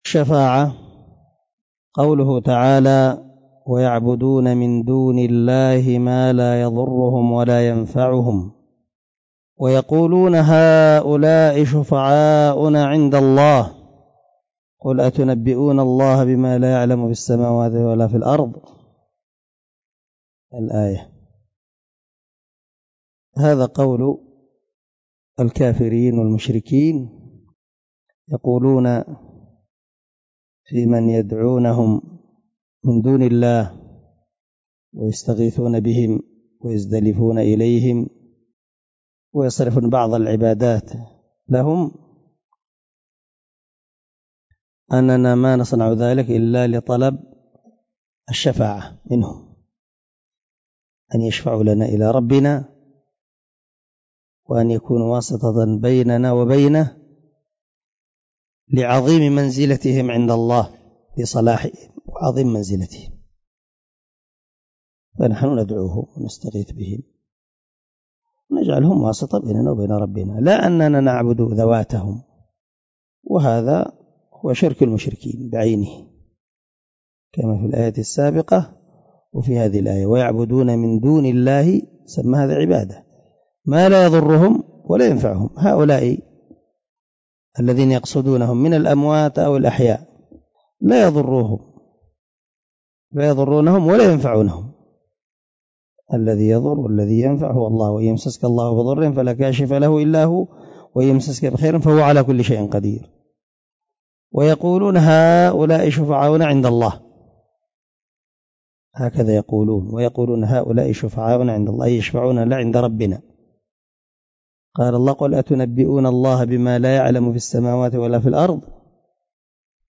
الدرس 5 تابع القاعدة الثانية دليل الشفاعة من شرح القواعد الأربع
الدرس-5-تابع-القاعدة-الثانية-ودليل-الشفاعة.mp3